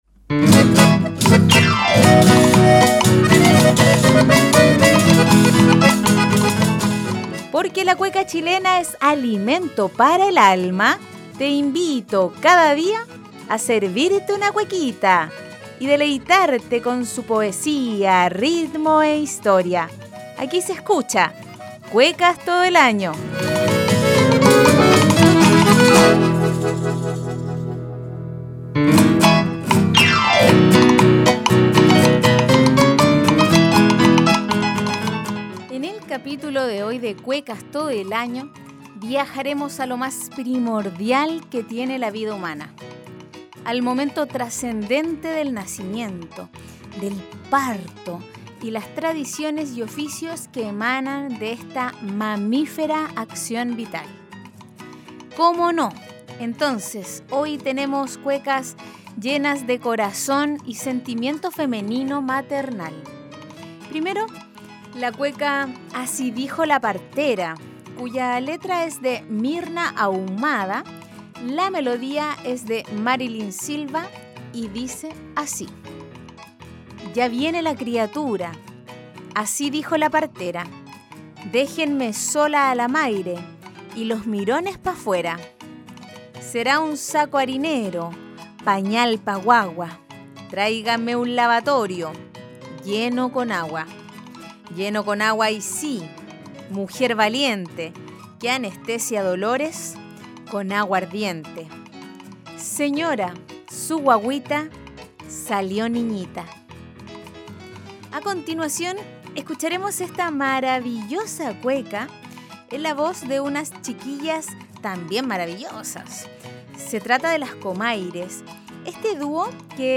Además de apreciar su poesía y conocer un poco más de su contexto de creación, la escucharemos en la voz de Las Comaires y Las Peñascazo.